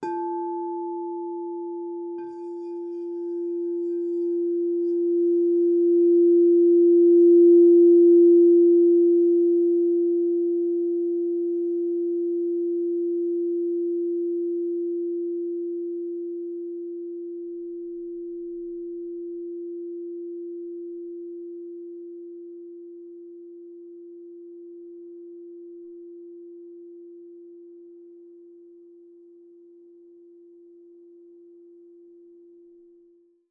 Meinl Sonic Energy 10" white-frosted Crystal Singing Bowl mit Griff F4, 432 Hz, Herzchakra (CSBH10F)
Die weiß mattierten Meinl Sonic Energy Kristallklangschalen mit Griff sind aus hochreinem Quarz gefertigt und erzeugen mit ihrem Klang und Design…